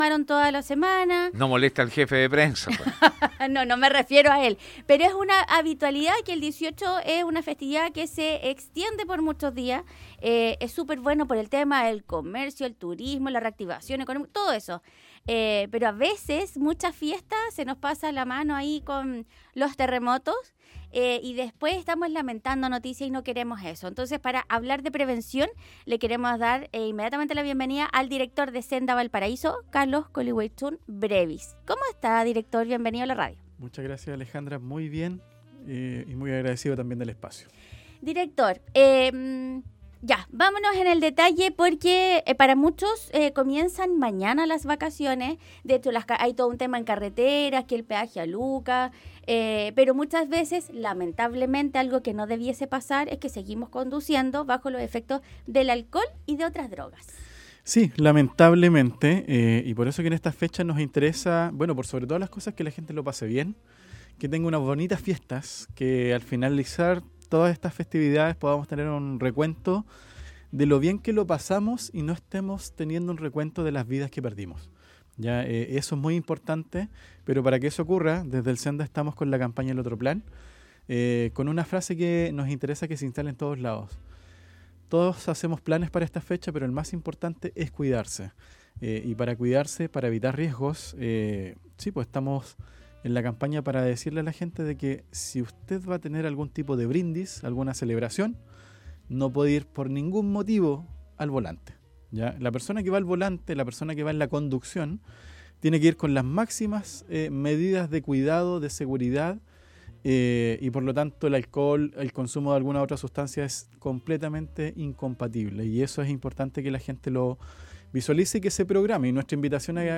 El Director de SENDA Valparaíso Carlos Colihuechun Brevis visitó los estudios de Radio Festival para hacer el llamado a la prevención, manejar con responsabilidad y pasar unas buenas festividades